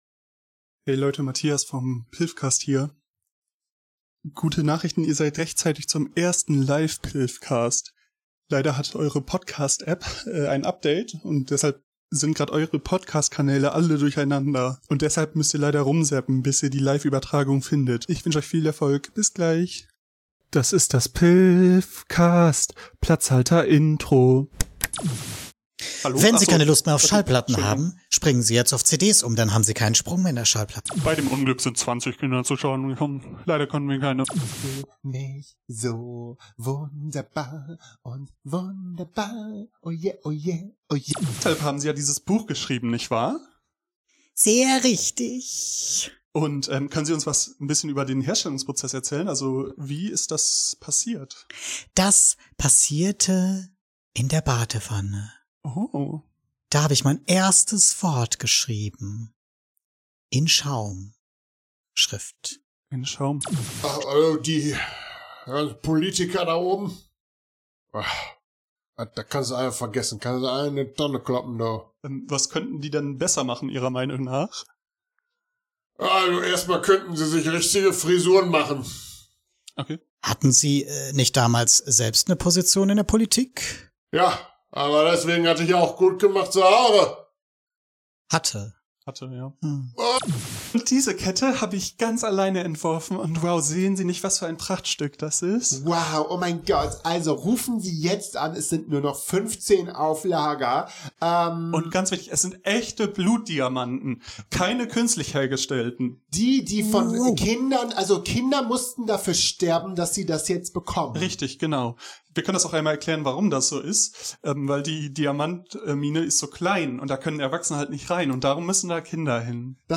PYLFcast #23 - Unser erster LIVE Podcast! ~ PYLFcast Podcast
Leider hatte dein Podcast Player ein Update, aber hier ist unsere erste Live Episode!